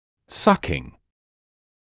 [‘sʌkɪŋ][‘сакин]сосание; грудной (о ребёнке); инговая форма от to suck